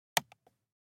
Switch Light Four